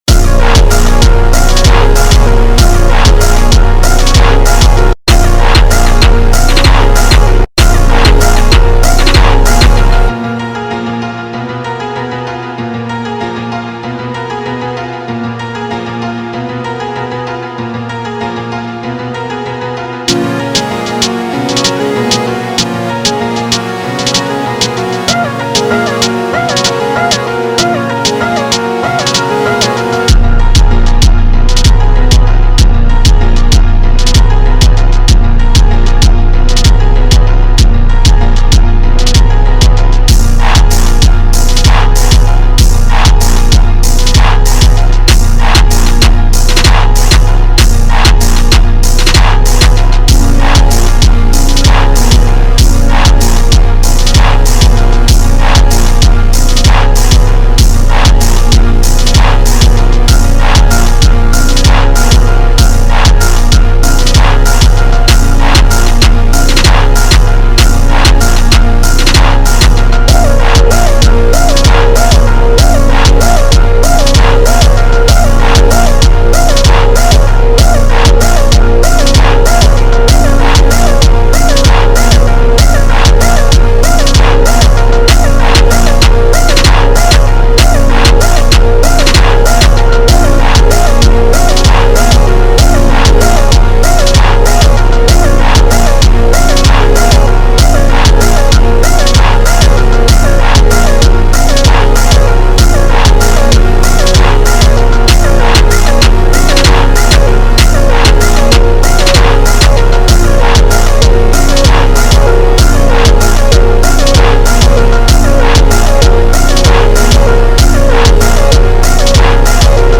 2025 in Jerk Instrumentals